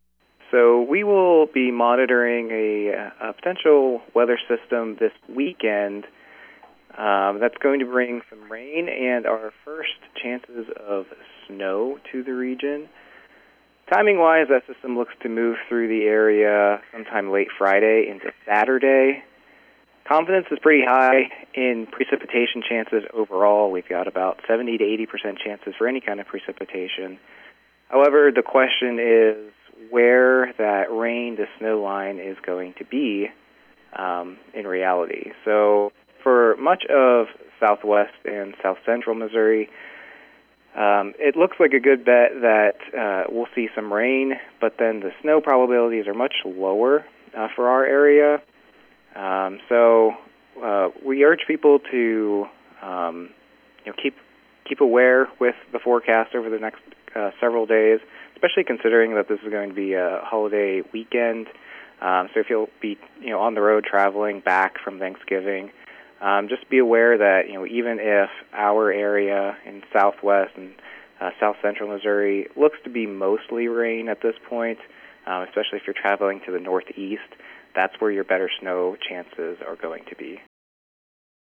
Meteorologist